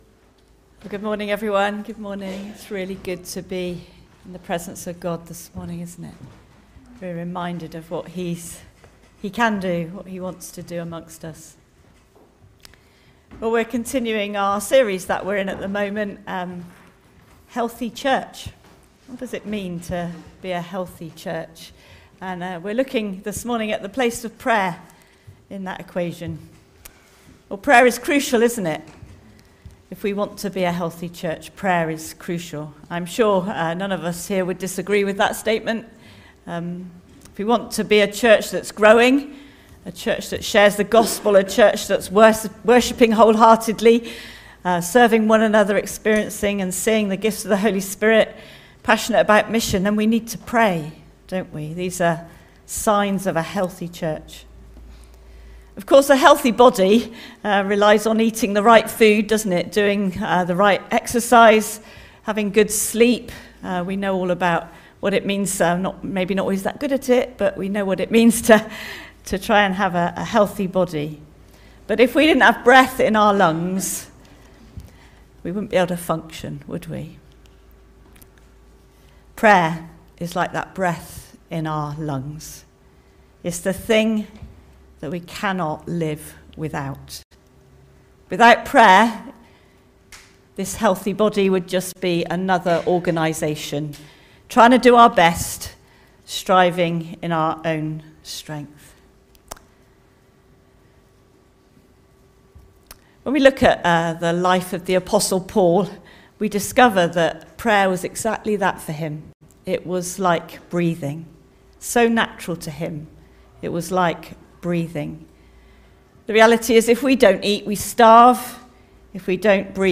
Sermon Audio - Christchurch Baptist Church